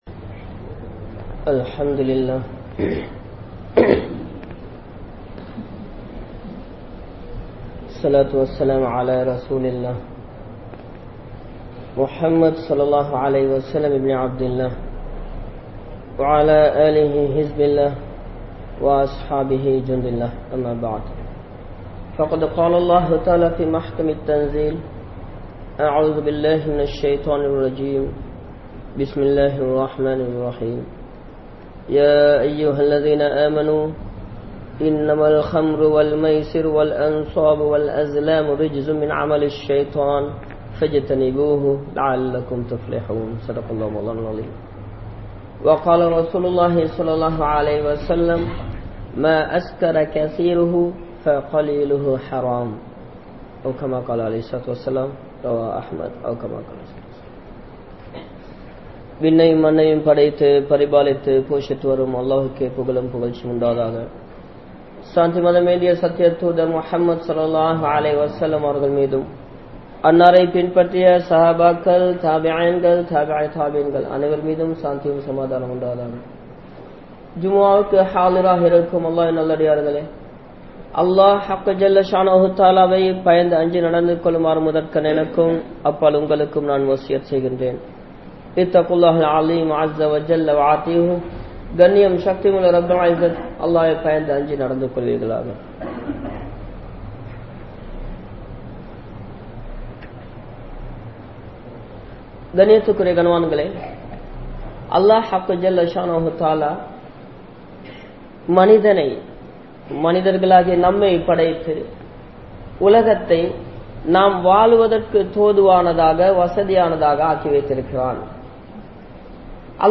Indraya Vaalifarhalum Boathai Vasthum (இன்றைய வாலிபர்களும் போதைவஸ்தும்) | Audio Bayans | All Ceylon Muslim Youth Community | Addalaichenai